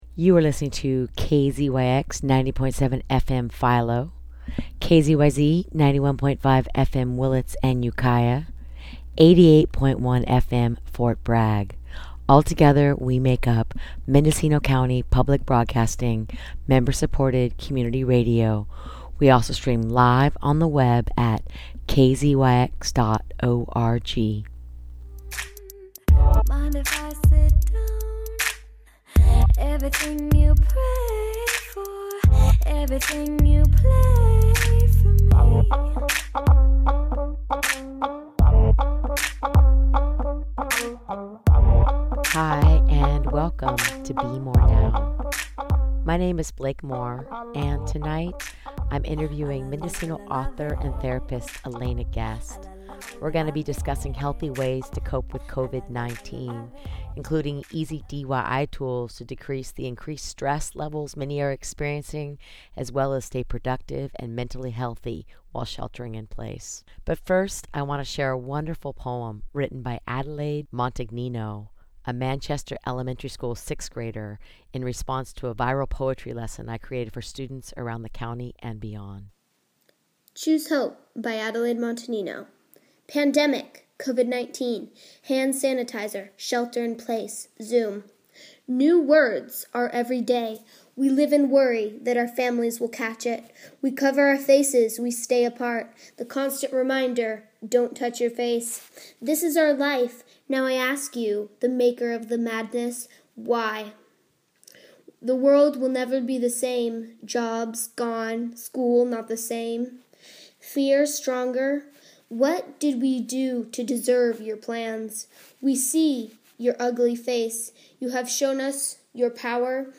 Maintaining safe distances by talking on the phone, they will discuss ways to cope with Covid-19, including some helpful tools to deal with anxiety, media overload, job uncertainty, financial stress, sleep deprivation, and most importantly, how to build a new reality in order to stay productive and healthy.
The show will also include poems by 6-8 grade students at Manchester Elementary School who wrote Covid-19 poems as part of my online “Viral Poetry Lesson”